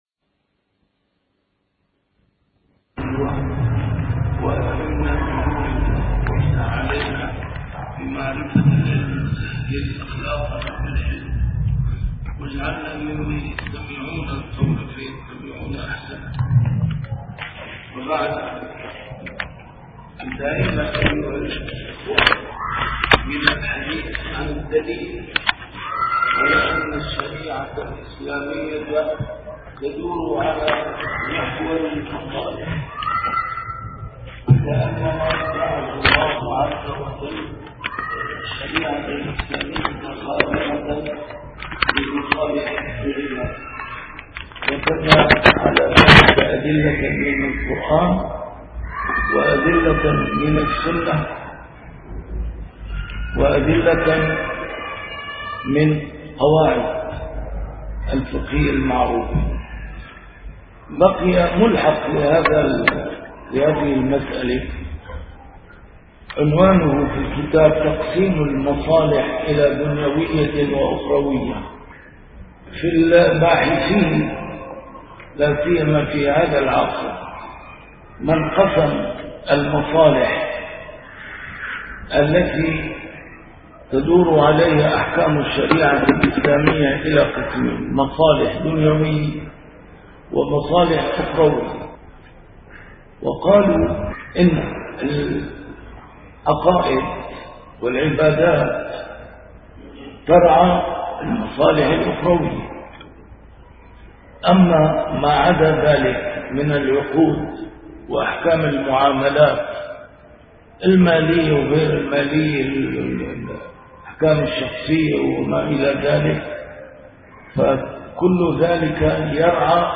A MARTYR SCHOLAR: IMAM MUHAMMAD SAEED RAMADAN AL-BOUTI - الدروس العلمية - ضوابط المصلحة في الشريعة الإسلامية - لا داعي في هذا المجال لتقسيم المصالح إلى أخروية ودنيوية (78-81).